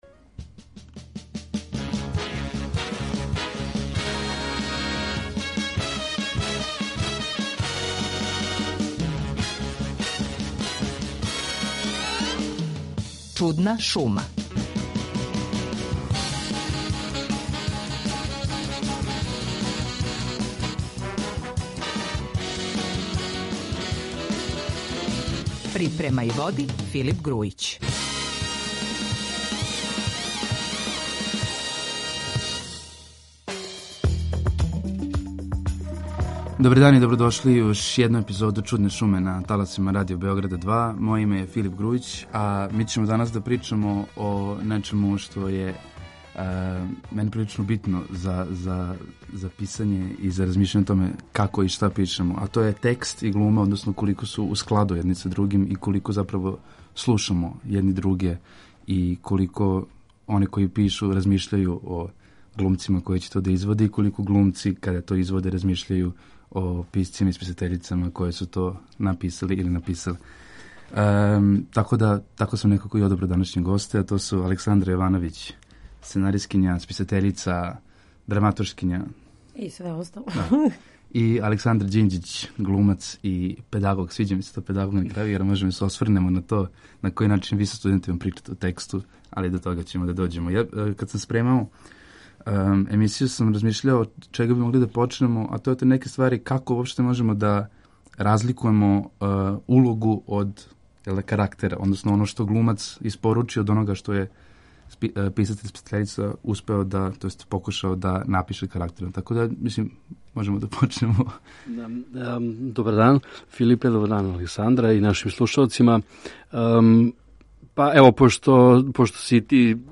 Глумац и сценаристкиња, написани текст у односу на изговорени